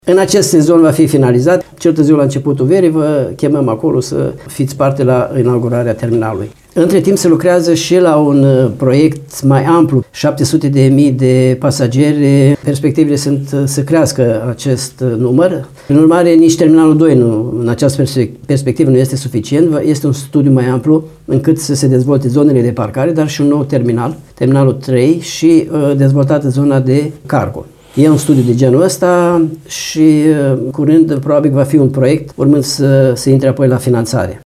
Vicepreședintele Consiliului Județean Suceava NICULAI BARBĂ a declarat postului nostru că proiectul se apropie de finalizare și a remarcat, totodată, intensificarea traficului aerian.